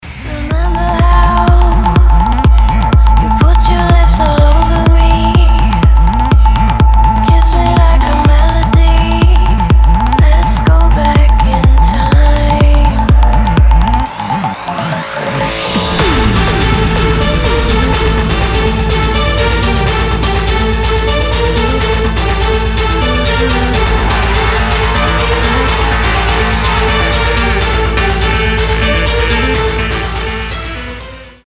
• Качество: 320, Stereo
громкие
женский вокал
восточные мотивы
Electronic
EDM
чувственные
progressive house
красивый женский голос
Чувственный прогрессив-хаус.